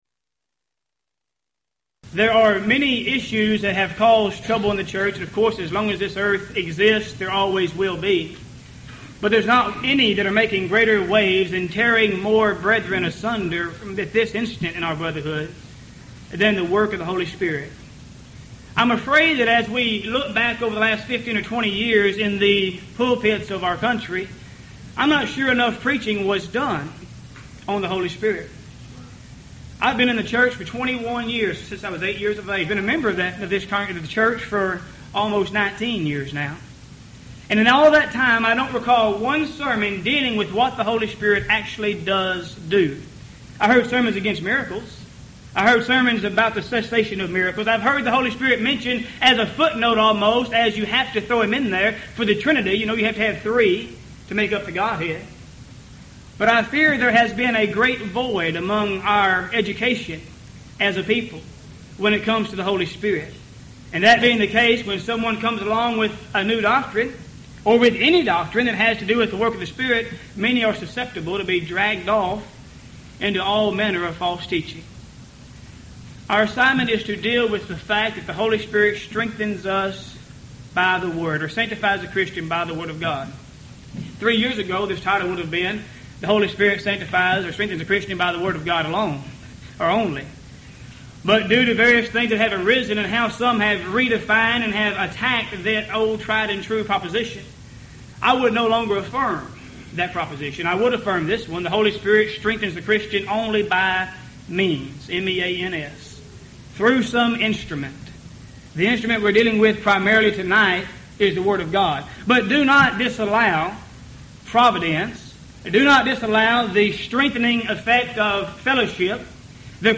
Event: 1998 Houston College of the Bible Lectures
If you would like to order audio or video copies of this lecture, please contact our office and reference asset: 1998Houston20